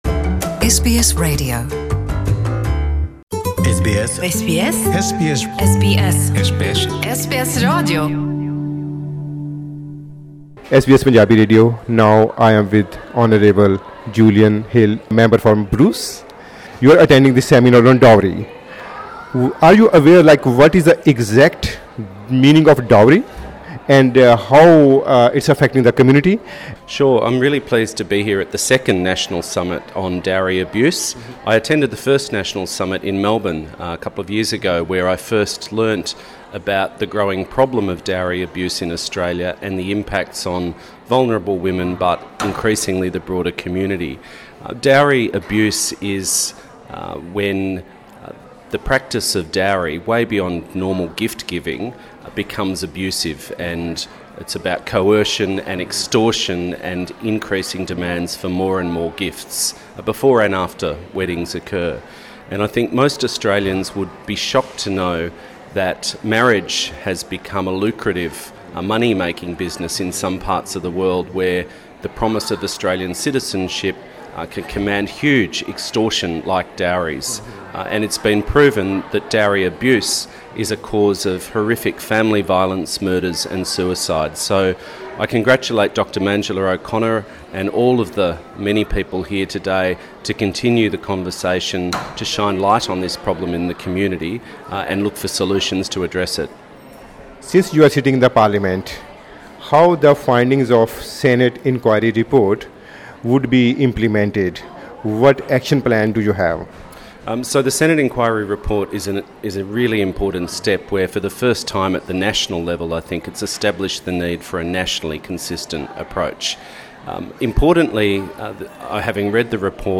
Attending the second dowry abuse summit in UNSW Sydney on Friday the 22nd Feb, Julian Hill MP told SBS Punjabi, ‘Dowry is supposed to be a cultural practice where the bride’s family give gifts before and after the wedding.